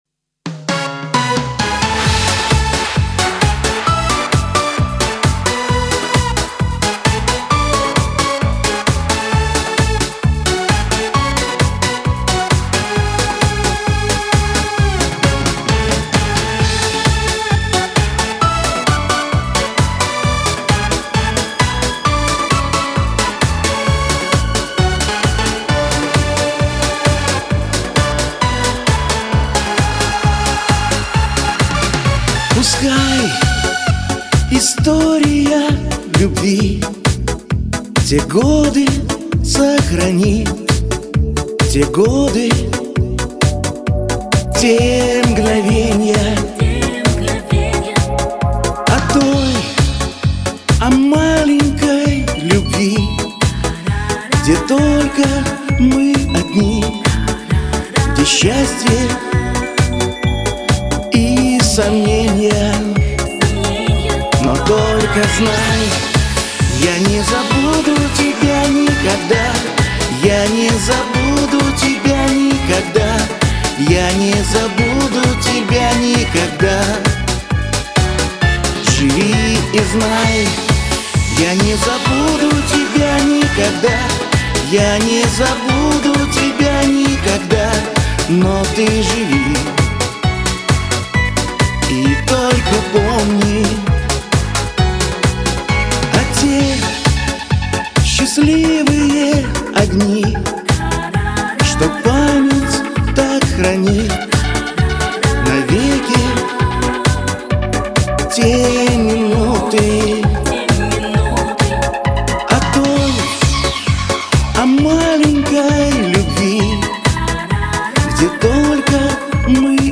звонко, громко, задорно))))